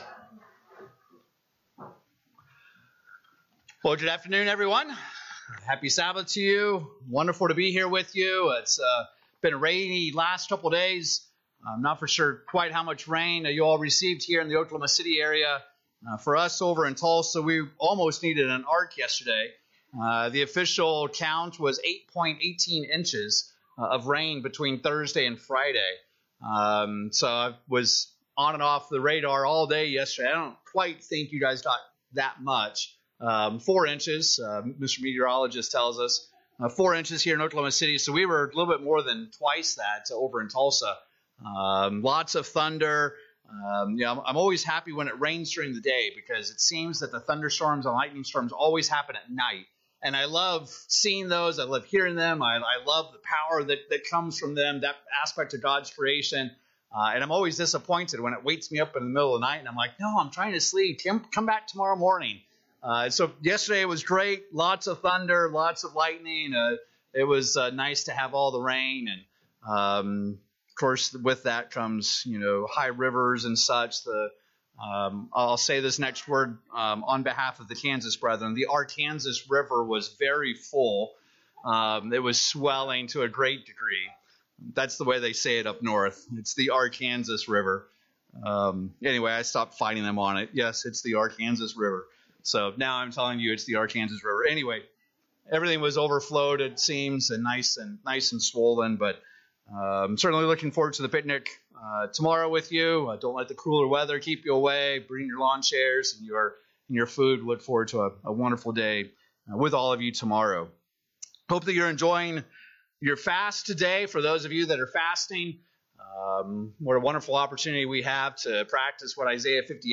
But when it comes to the Feast of Firstfruits, what should we do to prepare? In this sermon we'll examine the five sacrifices that Israel was required to bring on this particular Holy Day.
Given in Oklahoma City, OK